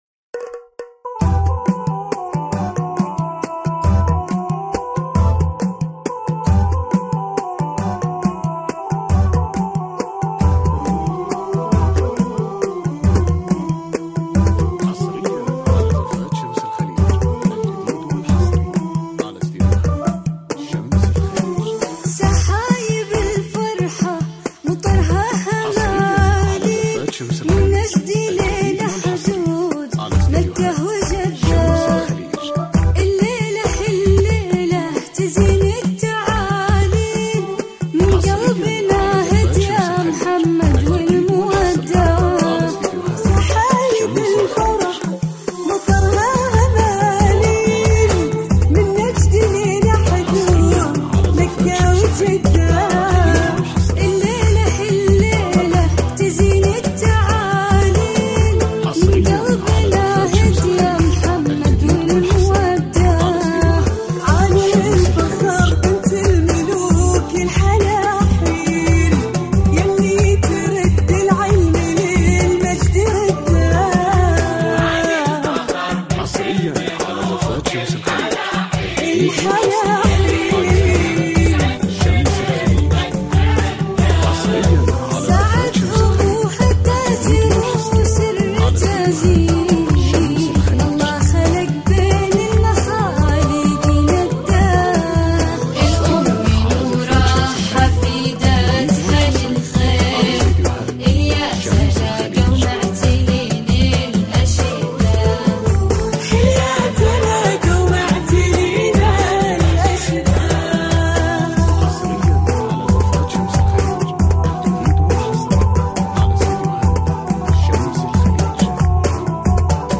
زفات بدون موسيقى